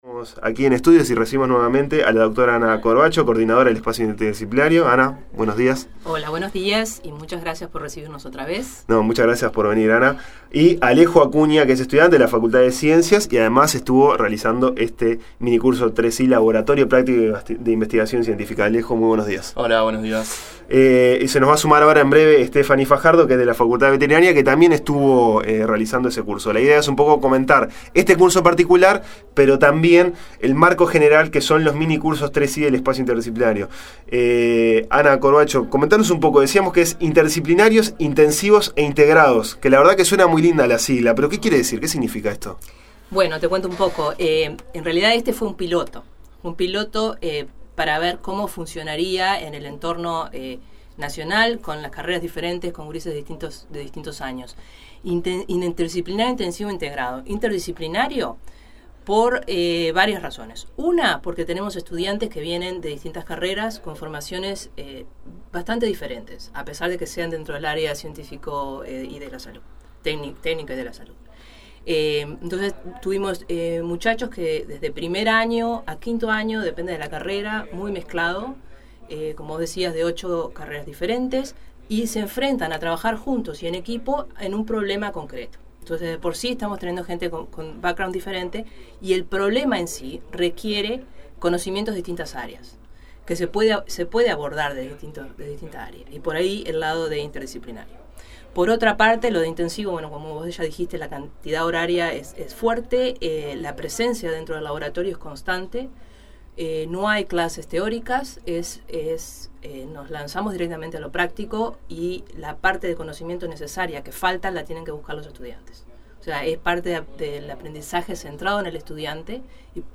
Para hablar de este curso, y de los minicursos 3i en general, recibimos en estudio